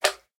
Minecraft Version Minecraft Version snapshot Latest Release | Latest Snapshot snapshot / assets / minecraft / sounds / mob / magmacube / small1.ogg Compare With Compare With Latest Release | Latest Snapshot